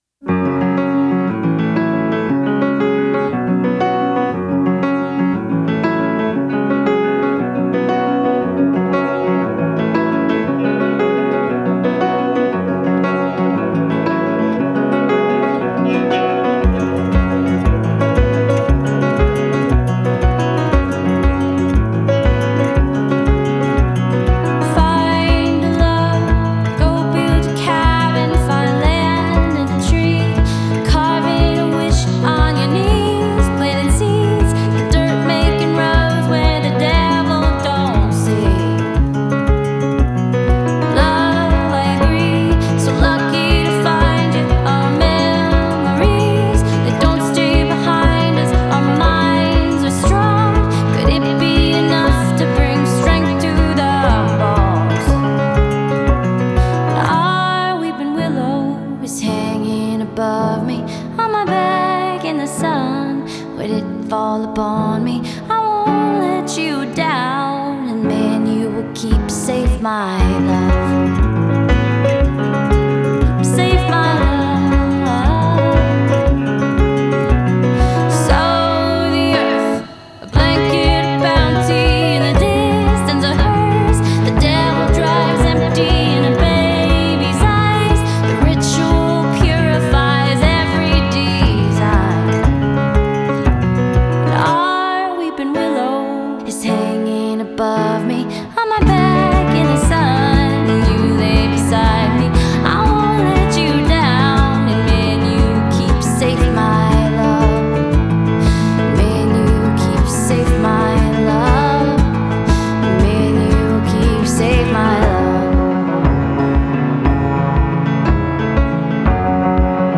Genre: Indie Pop, Pop Folk, Singer-Songwriter